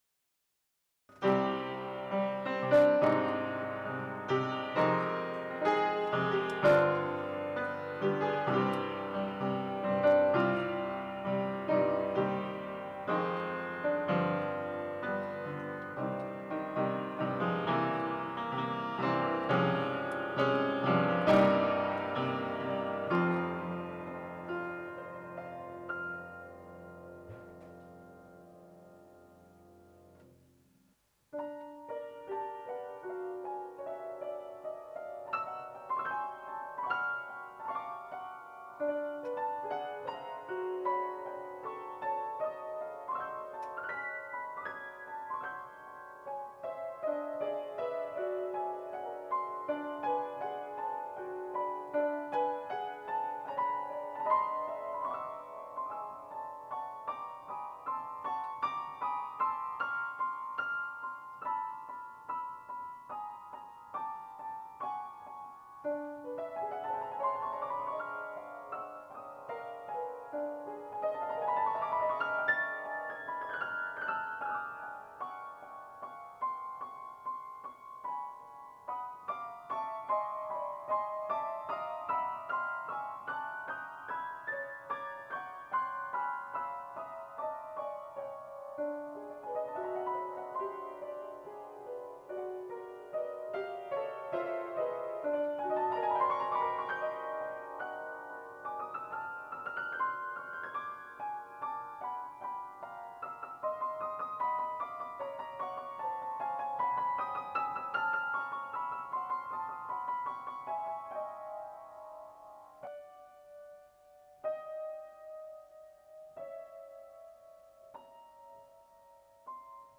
Personnellement, je pense qu'on est dans le hors-sujet total de part l'instrumentarium et les couleurs harmoniques et mélodiques; alors certes, il y a plus ou moins respect de la charte ( je ne distingue vraiment que 2 parties sur les 3 imposées ), on trouve une construction très intéressante pianistiquement, des motifs dissonants récurents, des passages atonals déchirants, des traits descendants et ascendants singuliers...on reste dans l'impressionisme et l'atonalisme, à cheval entre Debussy et Schoenberg ...